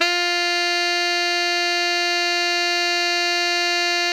Index of /90_sSampleCDs/Giga Samples Collection/Sax/GR8 SAXES MF
TENOR FF-F4.wav